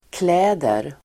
مفردات سويدية مع اللفظ (ytterkläder ,underkläder, kläder,dagis,del tid) - تعلم السويدية - نيروسبيك
ملابس (ثياب)=kläder=كليدر